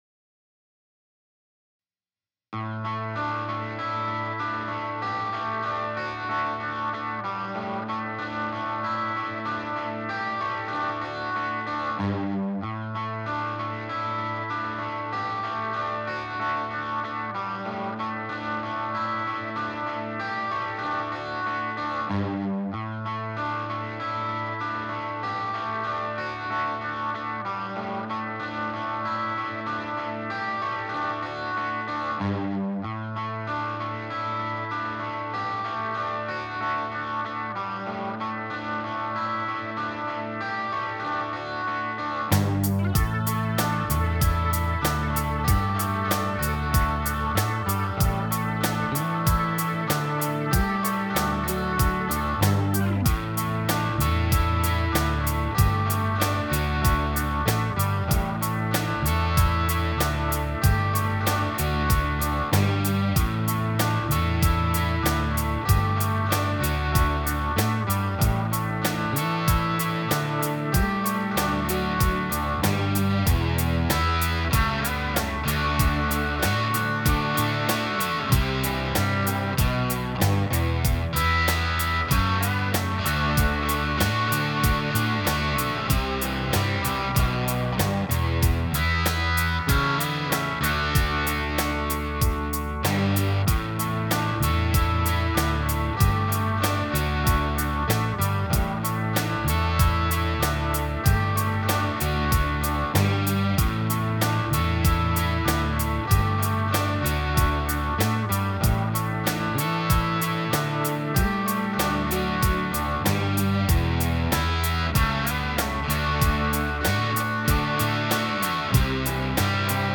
I think that's a little better, but it is very Hells Bells like.
I went with a Fender Twin sound. This time, cleaner. The clean sound is little less brittle, even with a little bit of break up.